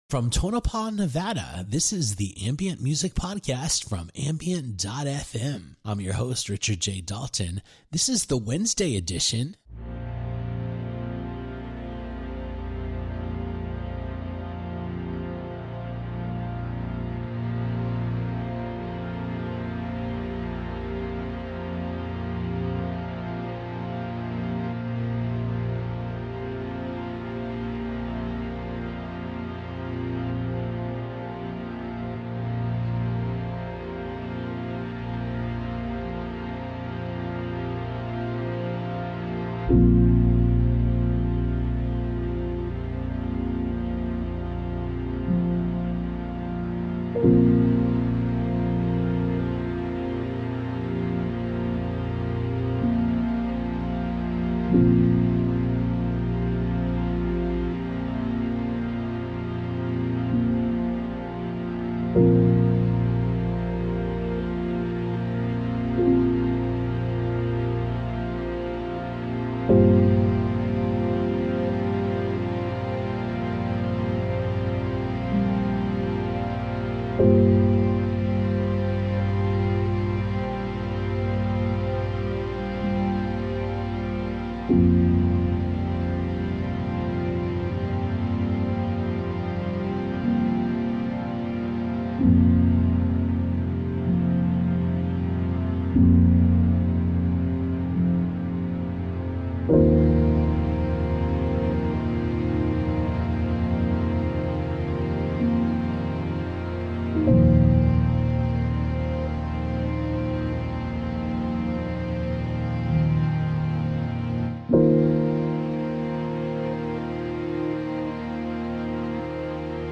ambient , atmospheric , Chill , Chillout , drone , electronic , electronica , experimental , instrumental
relaxation , soundscape